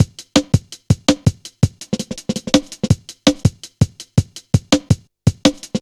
Index of /90_sSampleCDs/Zero-G - Total Drum Bass/Drumloops - 3/track 44 (165bpm)